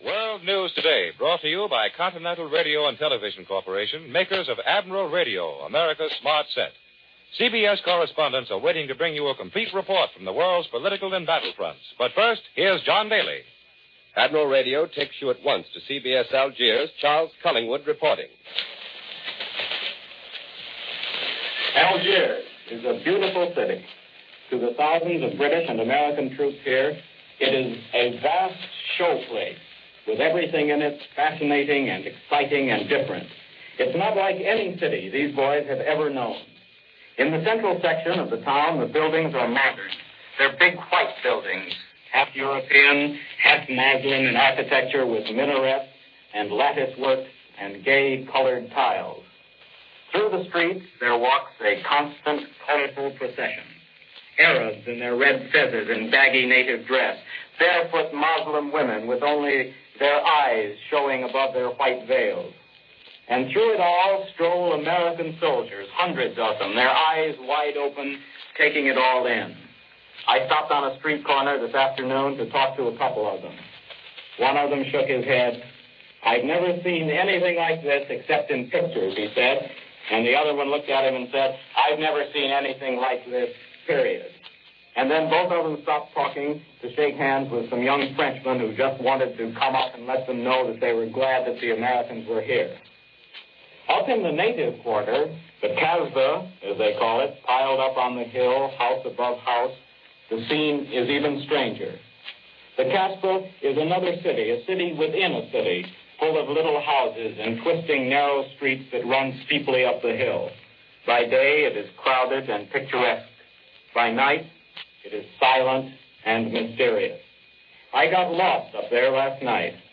November 15, 1942 – News from Algiers on this day, and there was a lot of it.